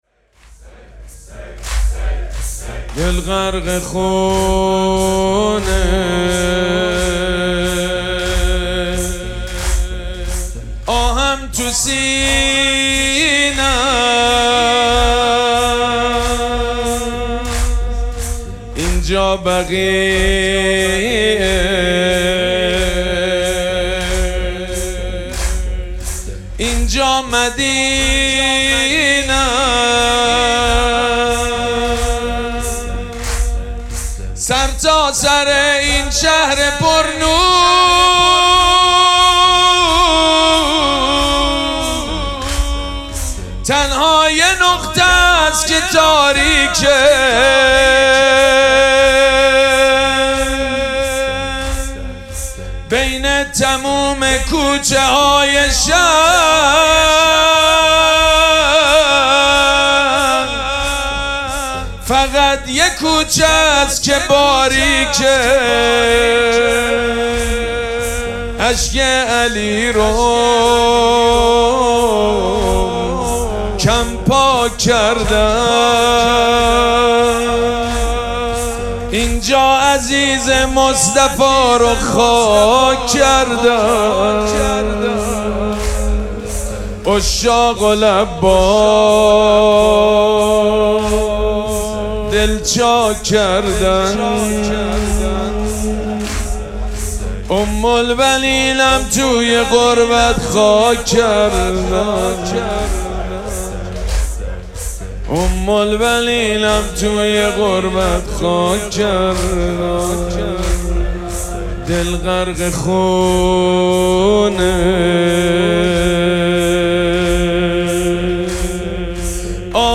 مراسم عزاداری شهادت امام صادق علیه‌السّلام
حسینیه ریحانه الحسین سلام الله علیها
مداح
حاج سید مجید بنی فاطمه